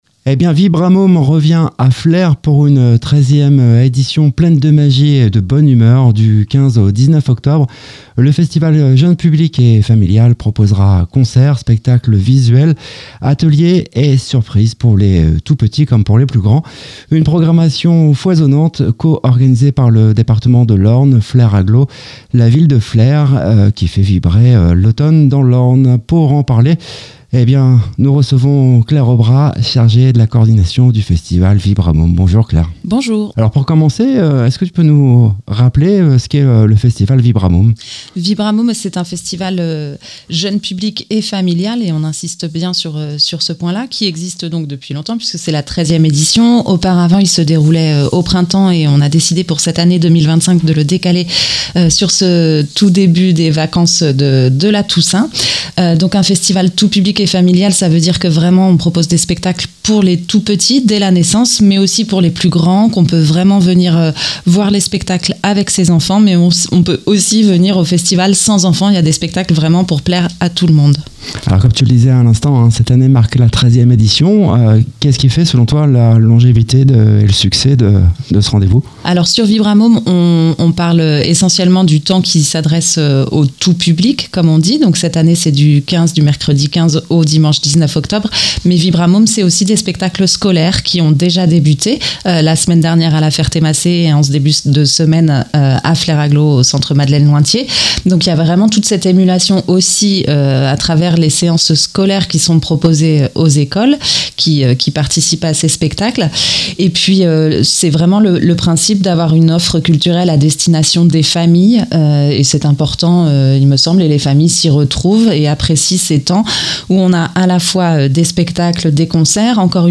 Elle nous raconte comment ce projet est né, ce qu’il propose concrètement aux familles, et pourquoi la musique est un outil précieux pour le développement des tout-petits. Un échange inspirant, entre engagement artistique et démarche éducative.